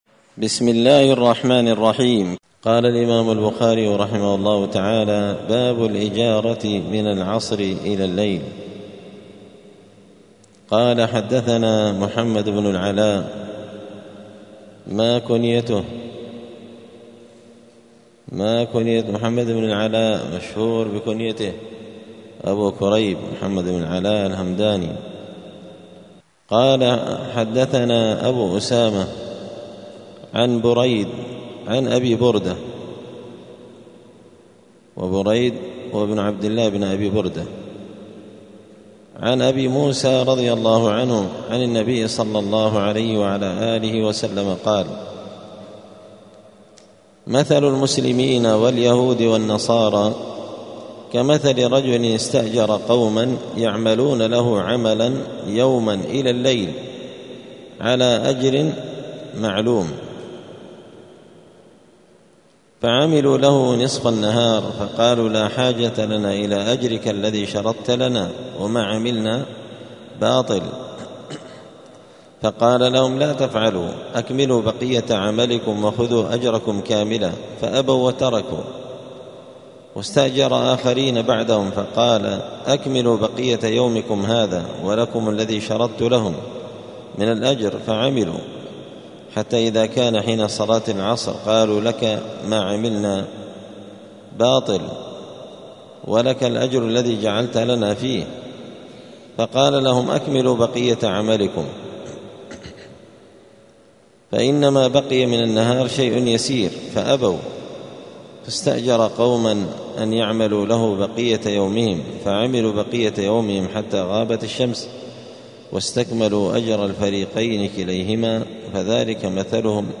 كتاب الإجارة من شرح صحيح البخاري- الدرس (8) باب الإجارة من العصر إلى الليل.
دار الحديث السلفية بمسجد الفرقان قشن المهرة اليمن